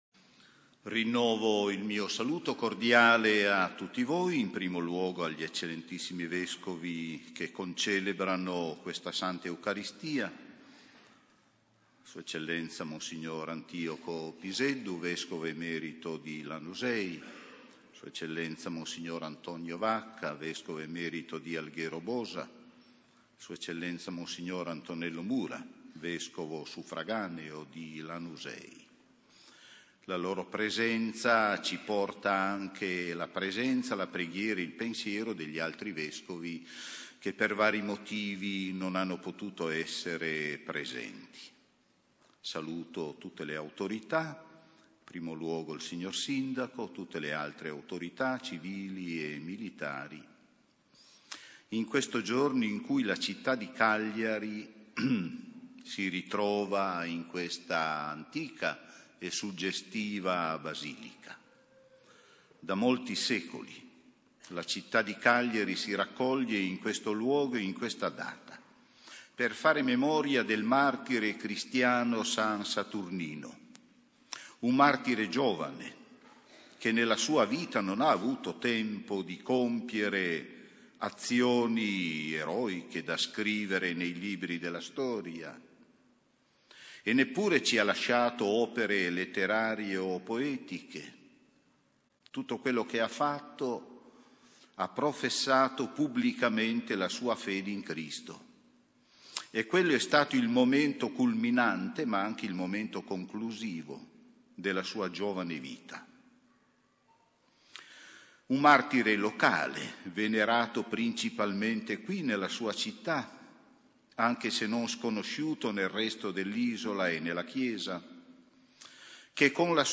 Omelia dell’Arcivescovo, Arrigo Miglio, pronunciata in occasione della festa di san Saturnino, patrono della città di Cagliari